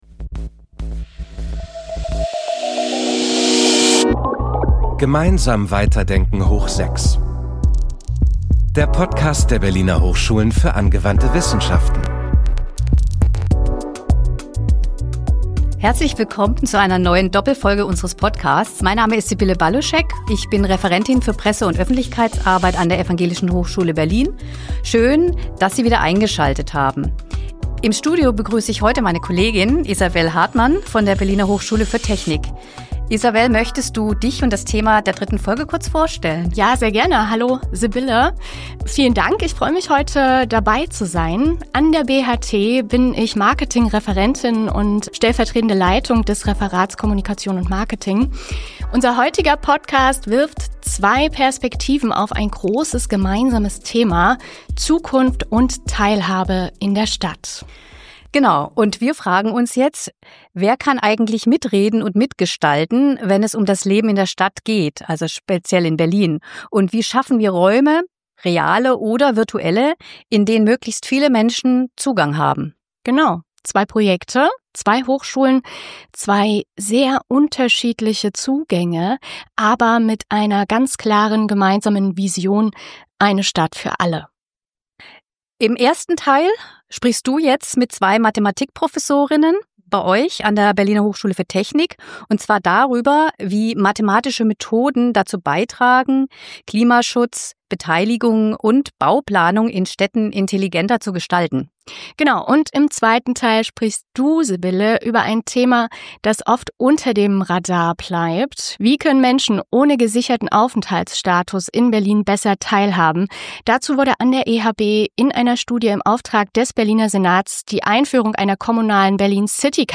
Sie zeigen, wie mathematische Methoden dazu beitragen, Klimaschutz, Beteiligung und Bauplanung intelligenter zu gestalten. Im Gespräch